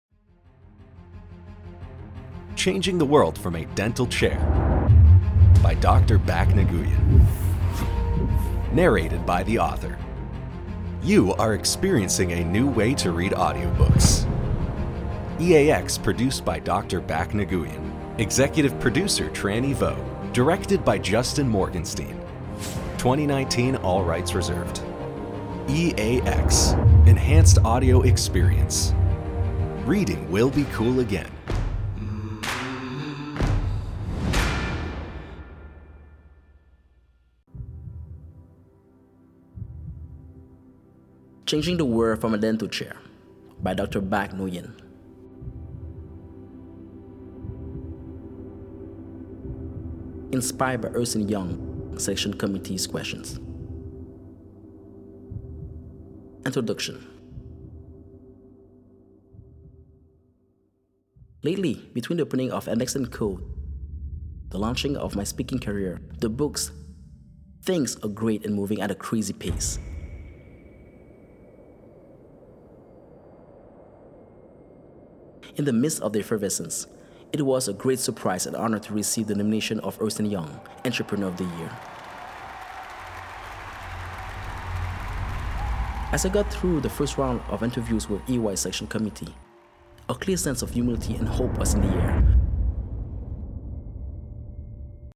UAX is the blockbuster of the Audiobooks.